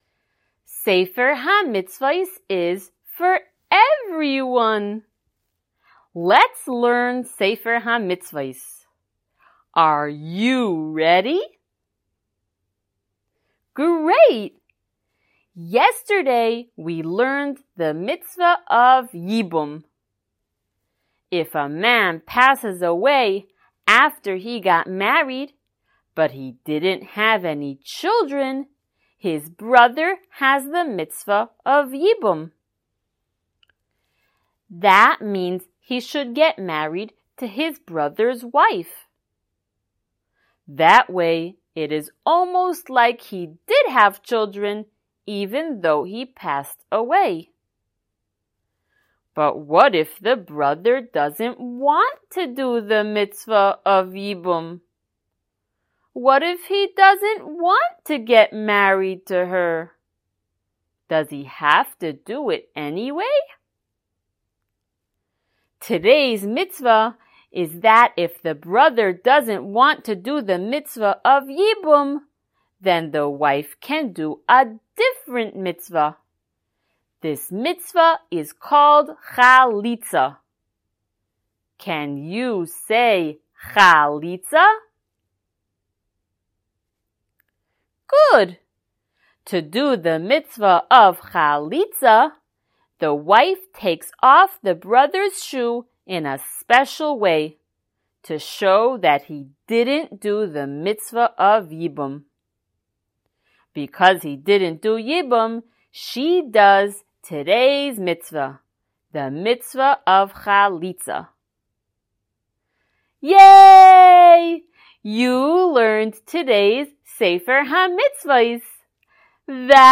SmallChildren_Shiur083.mp3